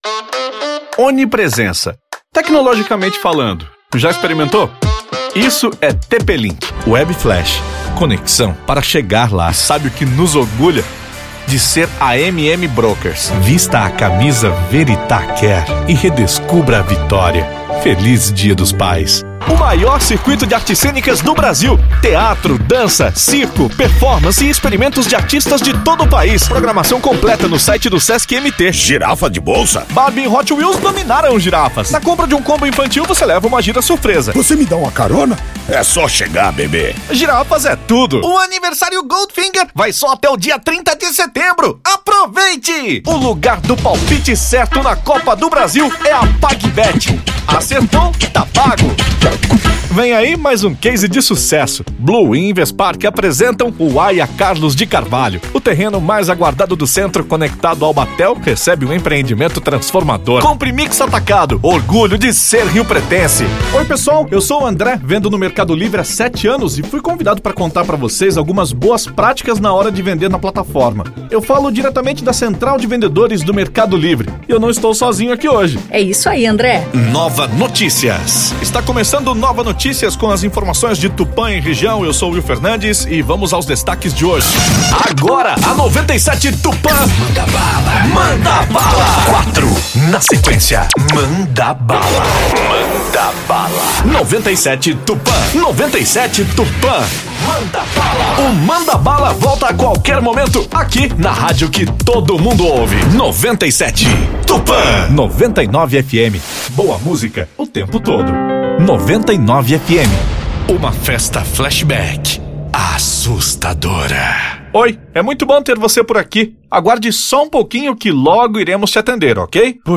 Voz humana com responsabilidade criativa.
Repertório completo
Locução para publicidade